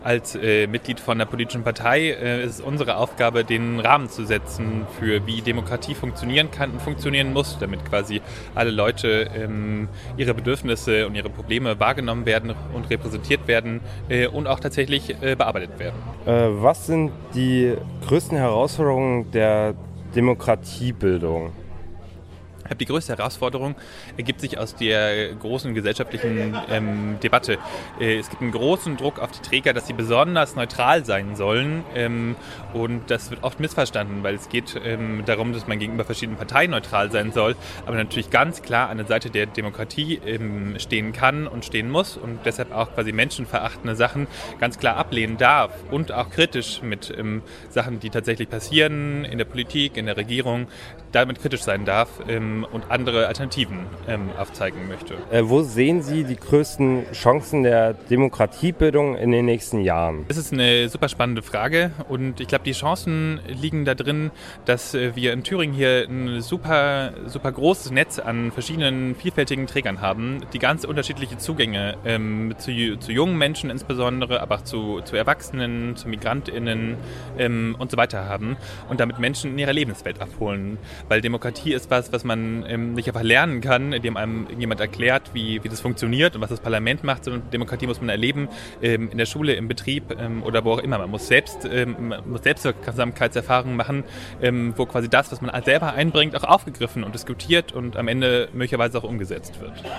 Sommerfest des Netzwerks Demokratiebildung Thüringen | Stimmen zum Nachhören
Radio F.R.E.I. und das Jugendforum Erfurt waren vor Ort und haben mit verschiedenen Beteiligten gesprochen.
Jasper Robeck, Mitglied im Landesvorstand von Bündnis 90/Die Grünen und Stadtrat in Erfurt, beschreibt zunächst seine Tätigkeit